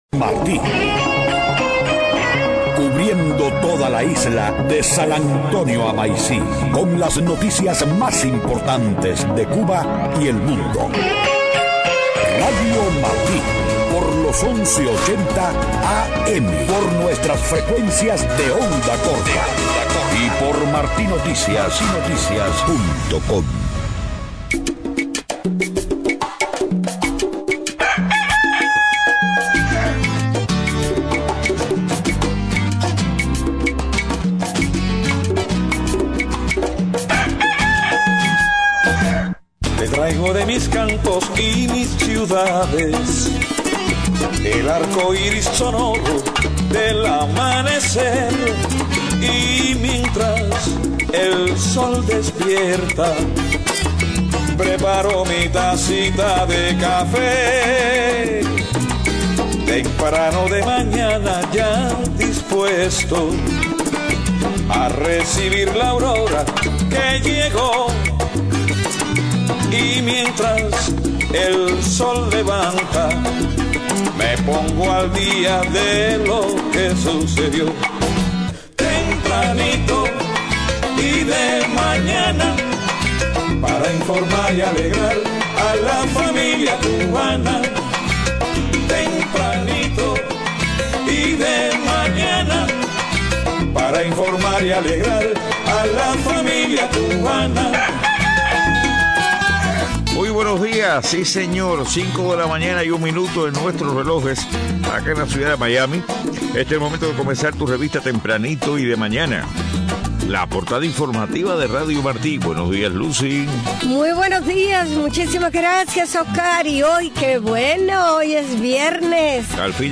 5:00 a.m. Noticias: Cuestionan calificación otorgada a Cuba por la ONU en materia de desarrollo humano. Rechaza el Gobierno de Venezuela la detención de funcionario diplomático venezolano en Aruba, a petición de EEUU.